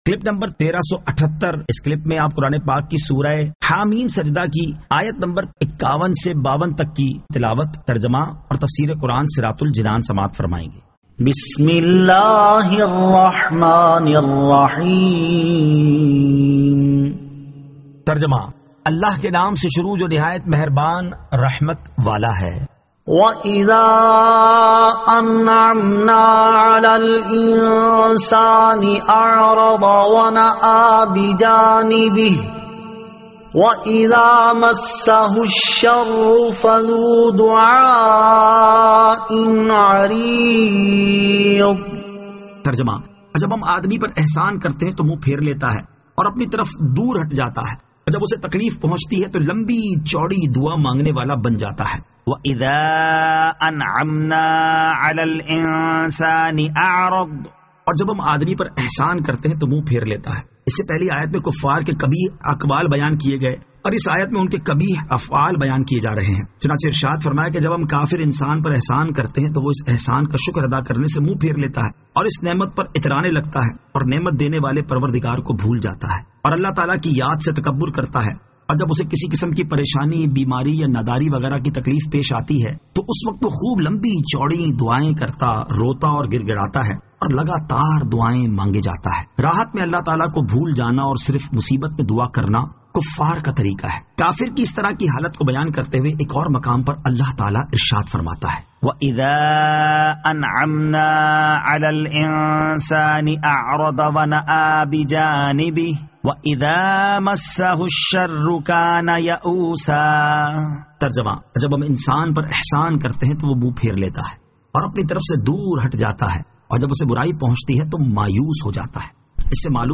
Surah Ha-Meem As-Sajdah 51 To 52 Tilawat , Tarjama , Tafseer